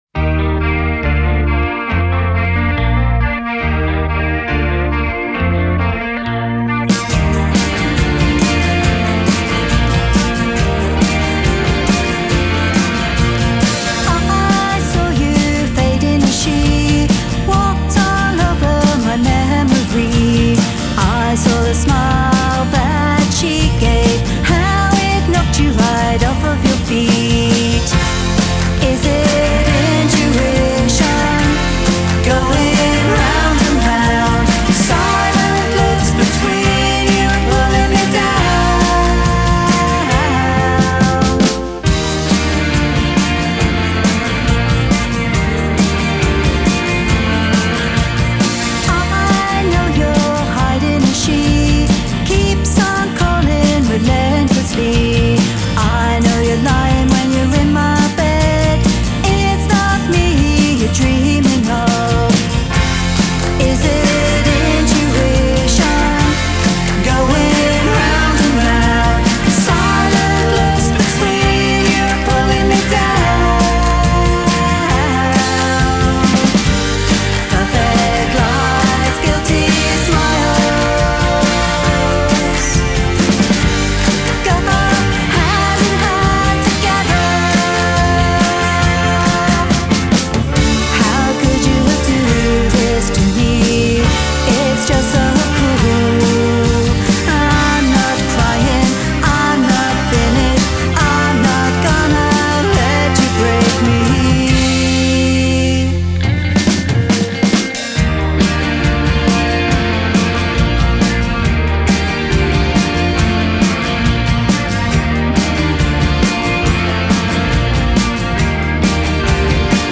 Delightful slice of Sunshine and Retro.
Ten playings so far and it still feels fresh and upbeat.